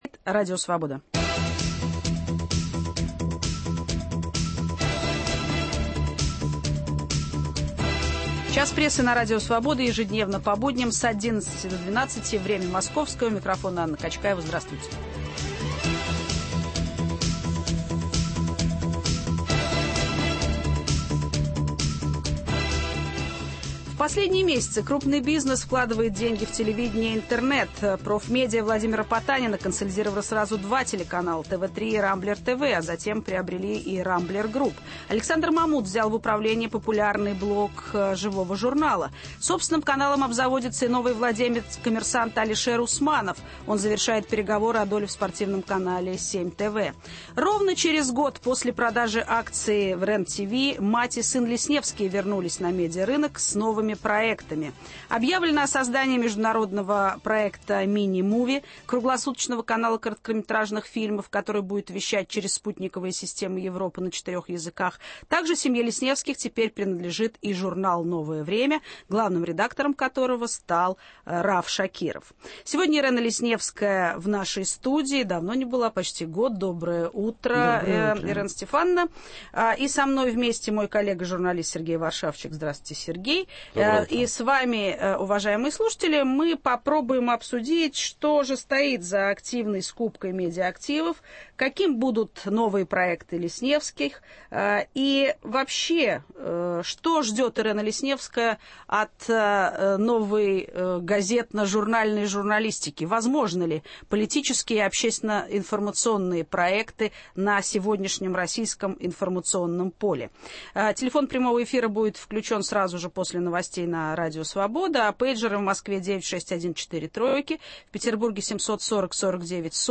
Ирэна Лесневская - в нашей студии.